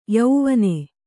♪ yauvane